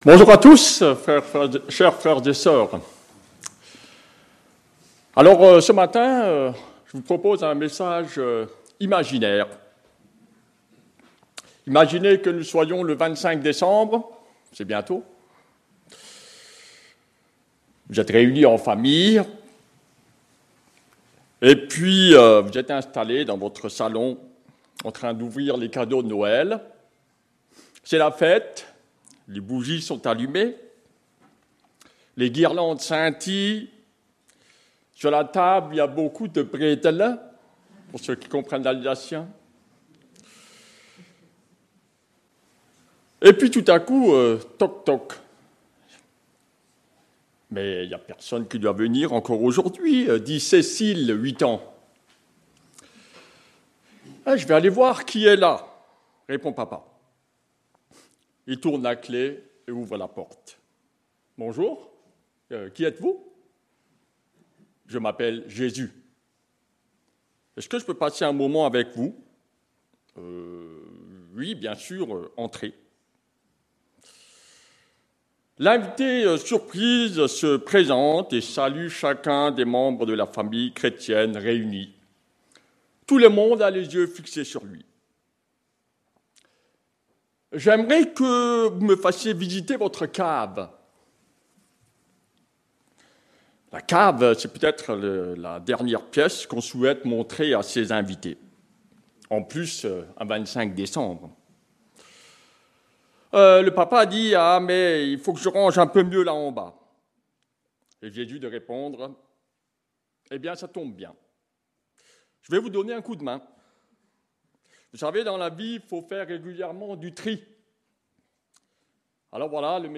Culte du dimanche 14 décembre 2025 – Église de La Bonne Nouvelle